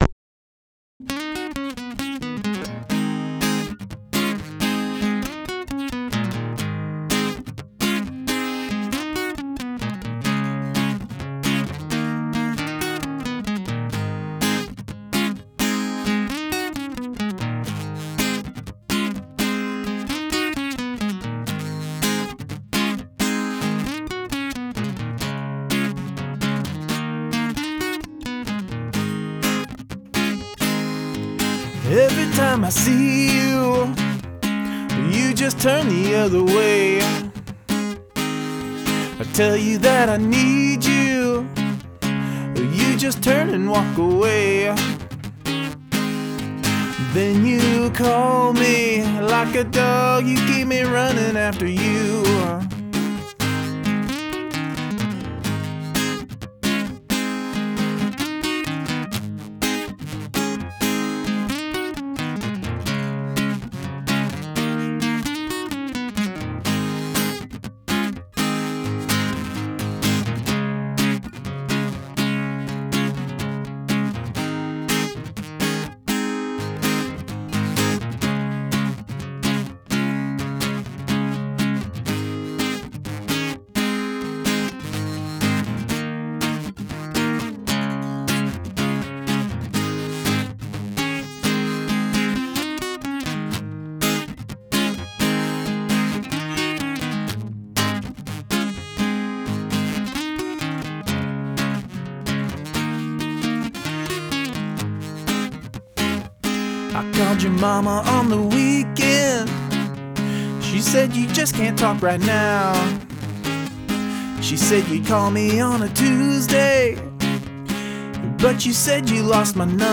A blues tune for the Song A Week.
Acoustic: Yamaha acoustic/electric
Vox: AT2020 Condenser
One take guitar. One Take Vox
Vinyl Effect: iZotope Vinyl plug in
Nice blues tune here.
That plugged in acoustic piezo sound always drives me crazy.